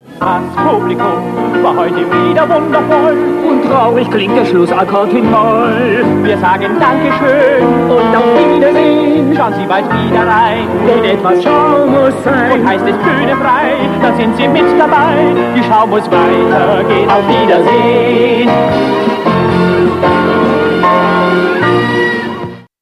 Outro Variante 3